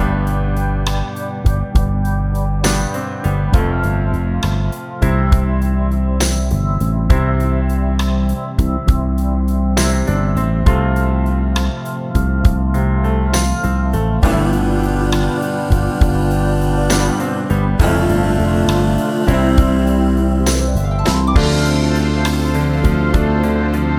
One Semitone Down Pop (1990s) 3:55 Buy £1.50